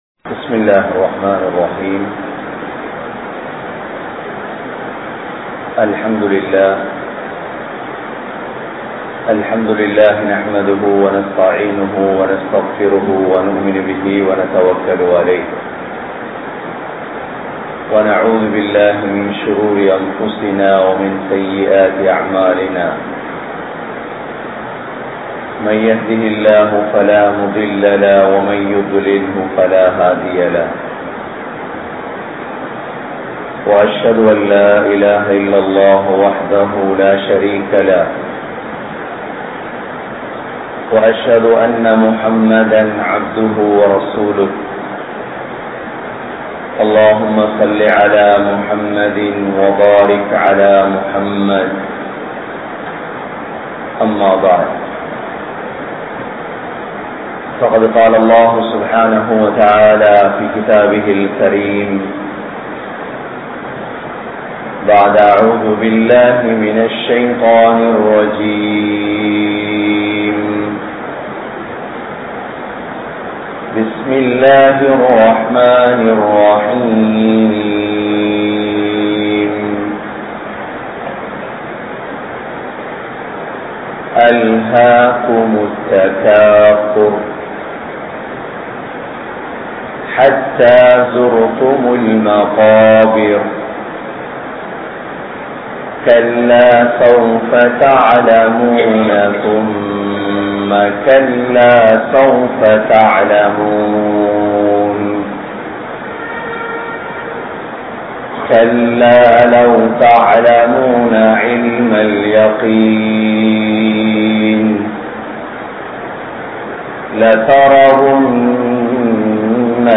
Indraya Muslimkalin Sinthanai (இன்றைய முஸ்லிம்களின் சிந்தனை) | Audio Bayans | All Ceylon Muslim Youth Community | Addalaichenai